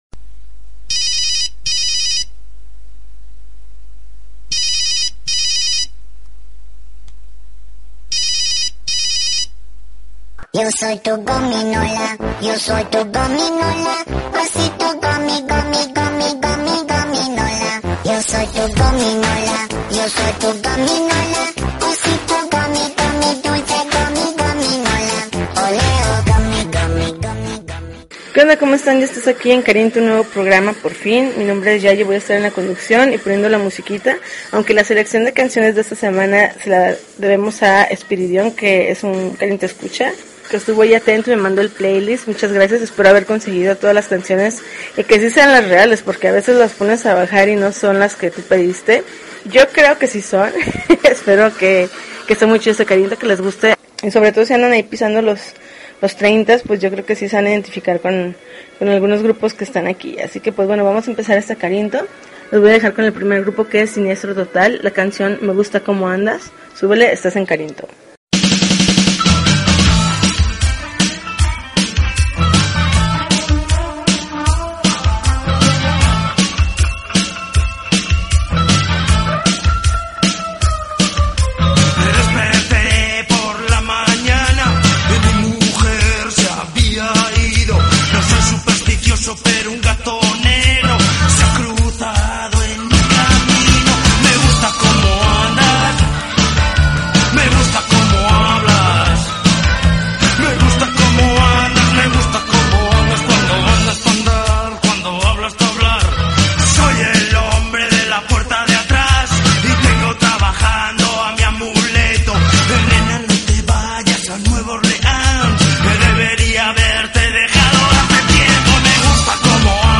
September 18, 2013Podcast, Punk Rock Alternativo